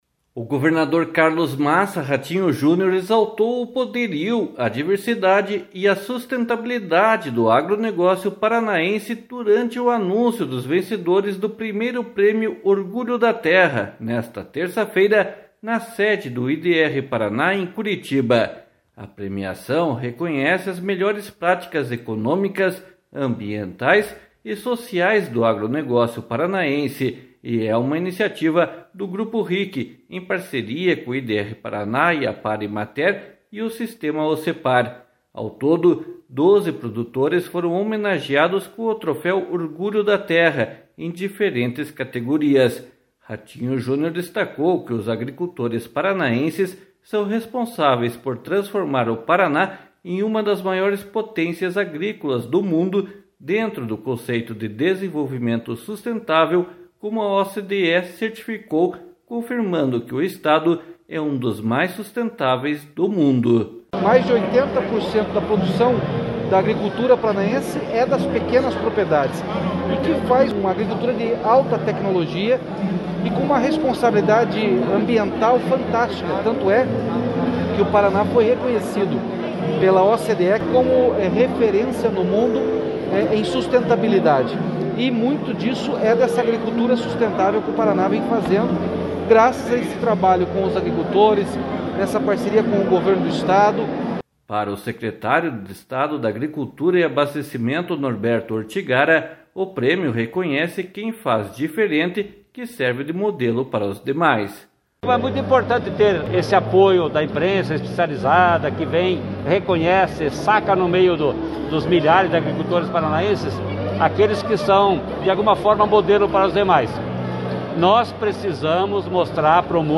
//SONORA RATINHO JUNIOR//
//SONORA NORBERTO ORTIGARA//